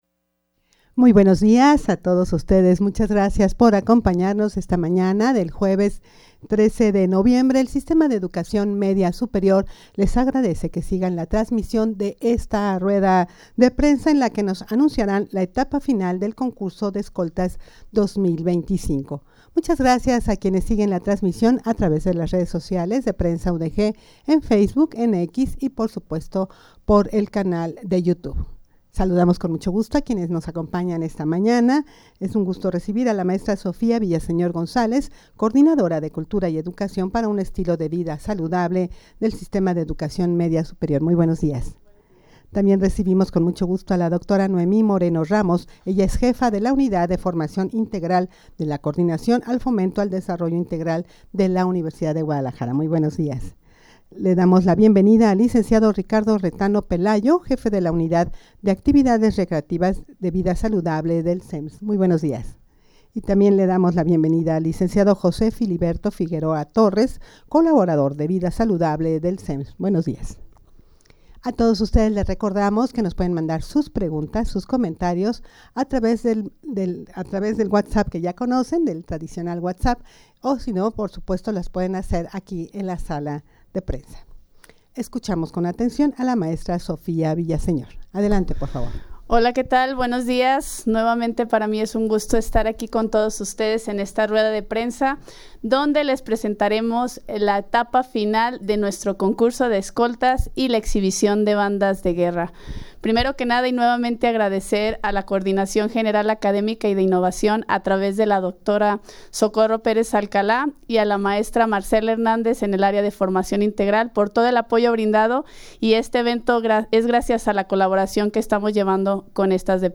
Audio de la Rueda de Prensa
rueda-de-prensa-para-anunciar-la-etapa-final-del-concurso-de-escoltas.mp3